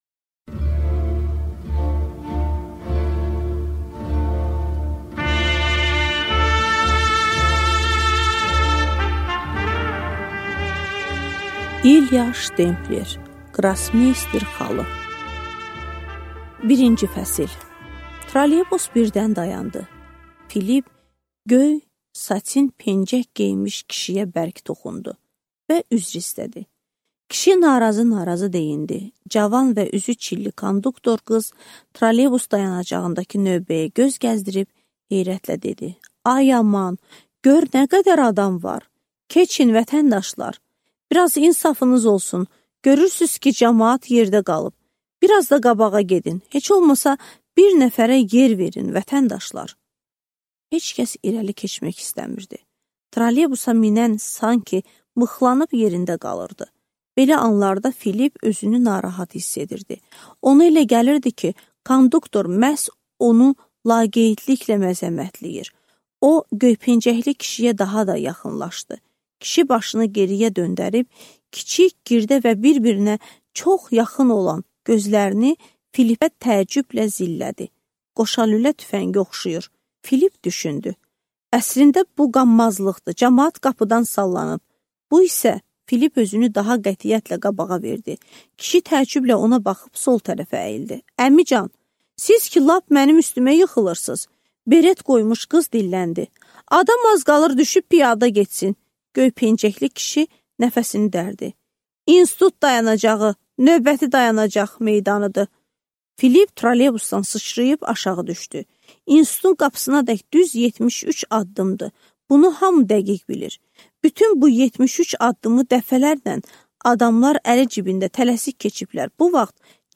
Аудиокнига Qrossmeyster xalı | Библиотека аудиокниг